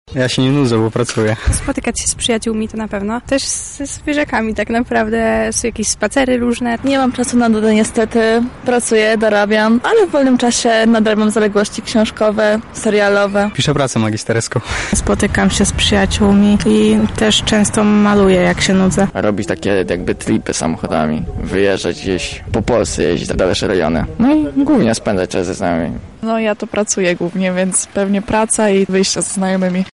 Zapytaliśmy mieszkańców Lublina jak radzą sobie z wakacyjną nudą:
Sonda wakacje